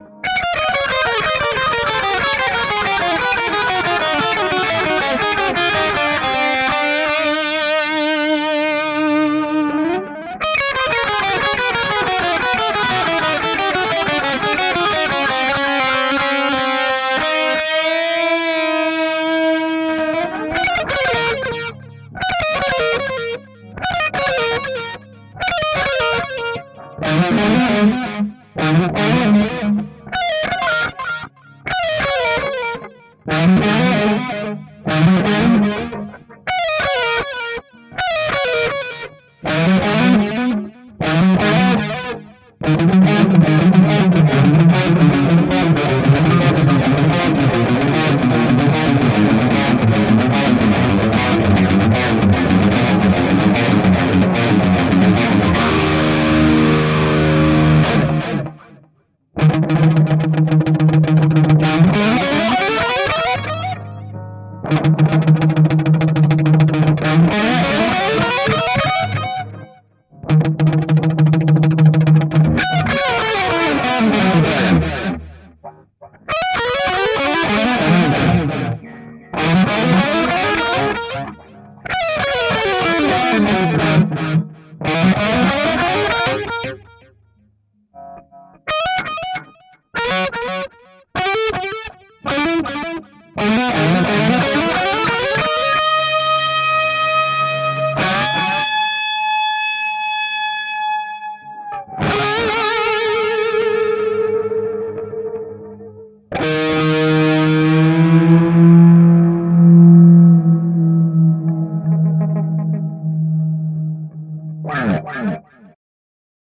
延迟级联 Eflat
描述：电吉他，高增益，重度延迟，一些干净，一些草率，快速音符，延迟几乎吞下音符
Tag: 小说 科幻 超速 延迟 失真 科学 空间 吉他